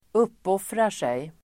Ladda ner uttalet
Uttal: [²'up:åf:rar_sej]